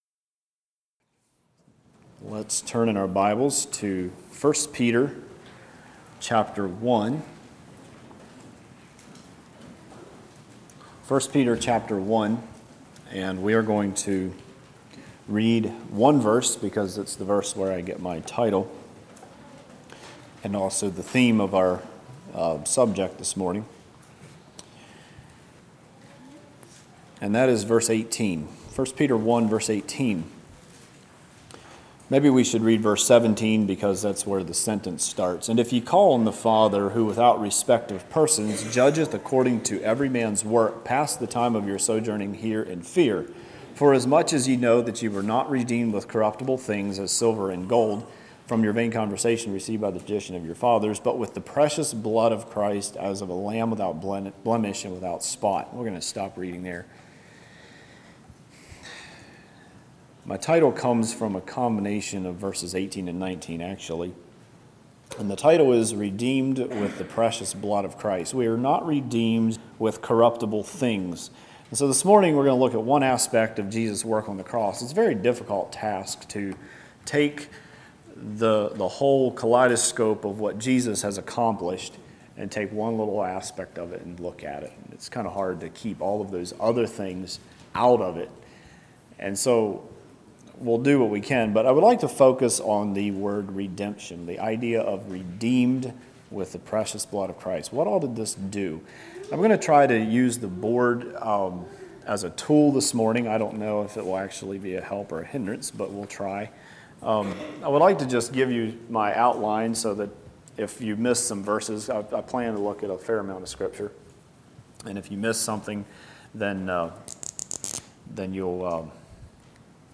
Play Now Download to Device Redeemed With The Precious Blood Of Christ Congregation: Darbun Speaker
Sermon